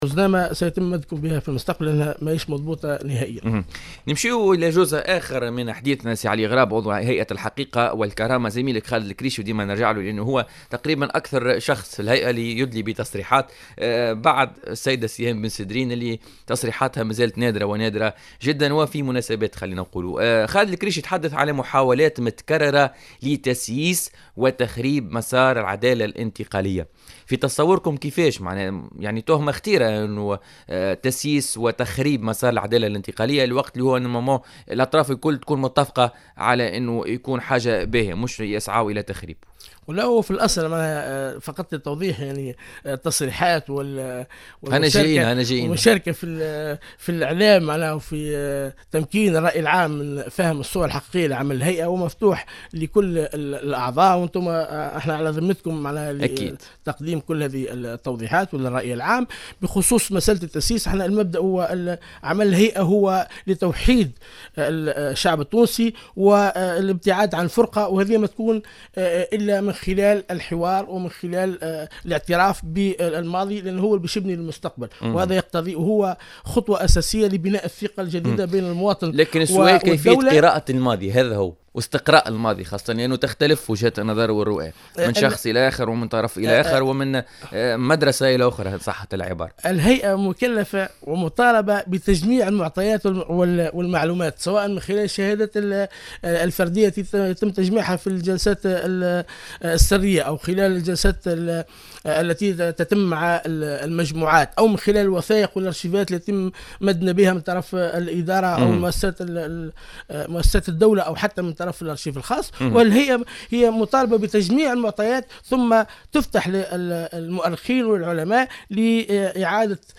أكد على غراب عضو هيئة الحقيقة والكرامة ضيف بوليتيكا اليوم الثلاثاء 4 أفريل 2017 أن عمل الهيئة يرتكز على توحيد الشعب التونسي والابتعاد عن الفرقة معتبرا أن هذا الهدف لن يتحقق إلا من خلال الحوار والإعتراف بالماضي وهو خطوة أساسية لبناء الثقة بين المواطن والدولة.